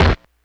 kick01.wav